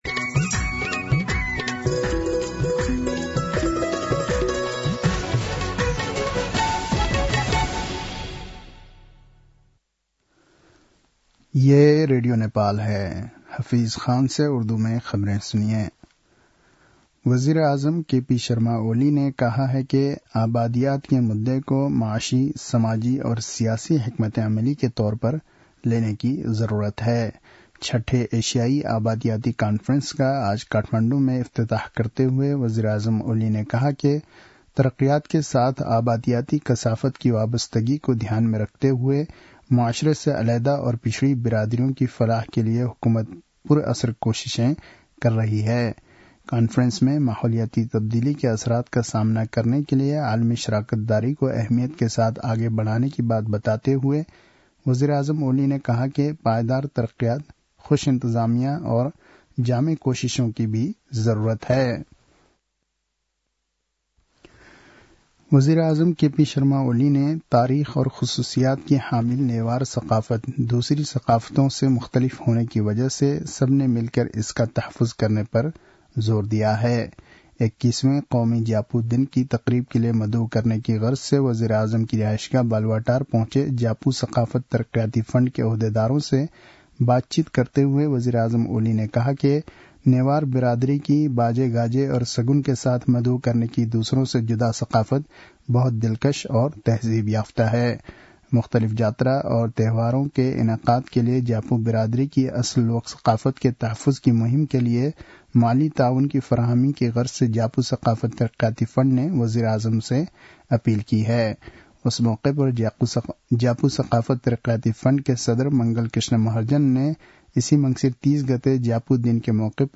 उर्दु भाषामा समाचार : १३ मंसिर , २०८१
Urdu-news-8-12.mp3